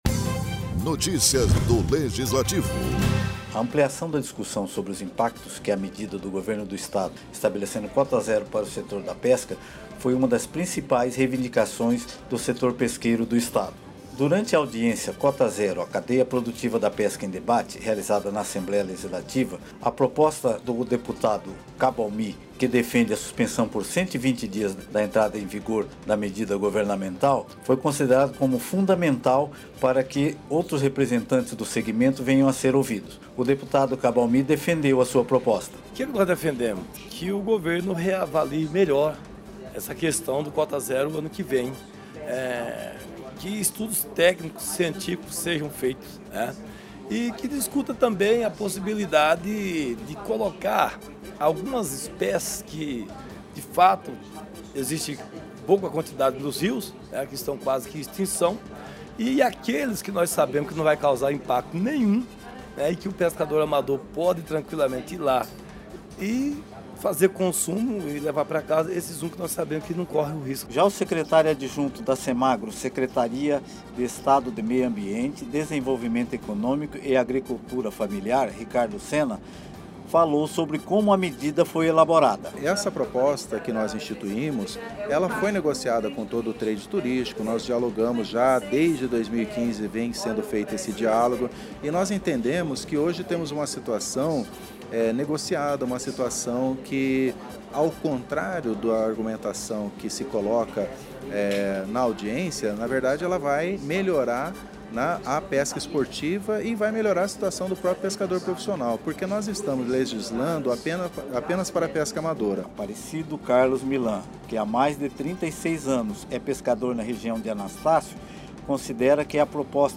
Audiência Cota Zero a Cadeia Produtiva da Pesca foi realizada na Assembleia Legislativa, nesta quinta-feira (21).